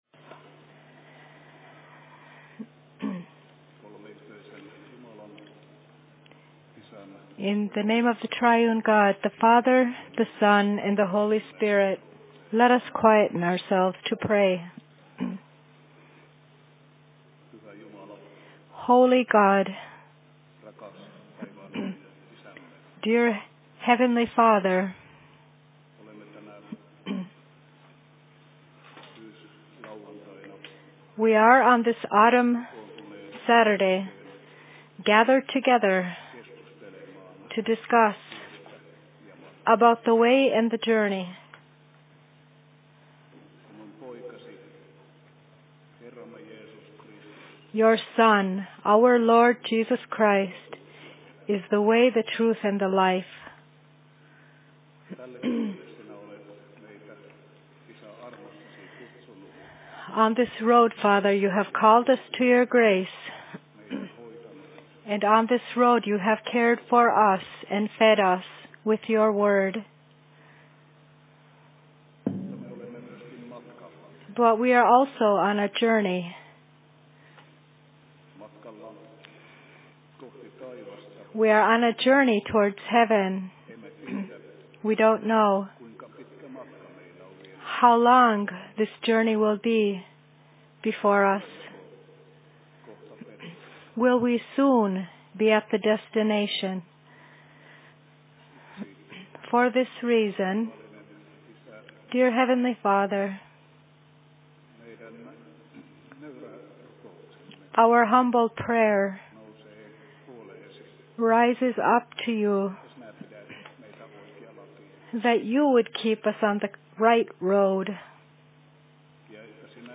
Youth Evening/En Presentation on Oulu RY 30.10.2021 18.04
Location: Rauhanyhdistys Oulu